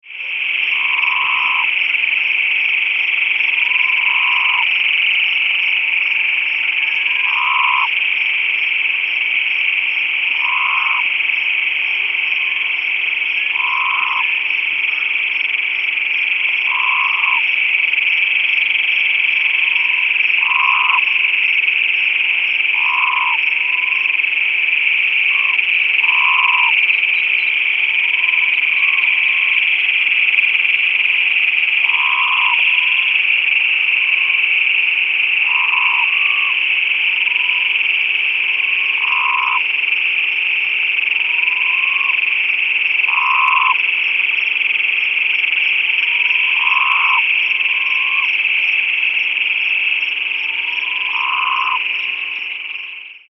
Sonoran Desert Toad - Incilius alvarius
Advertisement Calls
The call of the Sonoran Desert Toad is a weak, low-pitched whistling screech, 1/2 - 1 second in duration that cannot be heard as far away as many frogs and toads.
Sound This is a 53 second unedited recording of the advertisement calls of a distant group of Sonoran Desert Toads, made at night in Pima County, Arizona. One toad can be heard in the foreground with more in the background. A cacophony of Great Plains Toads and insects is heard in the foreground.